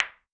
Ball Impact Distant.wav